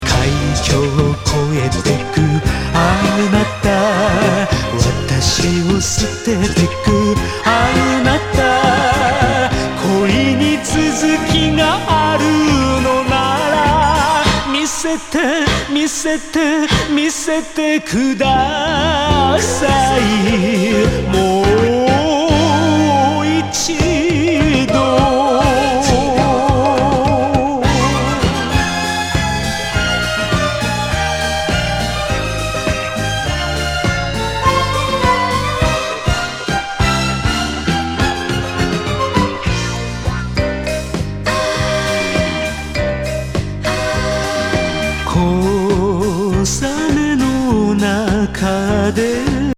ワウワウ・ファンキー演歌グルーヴ!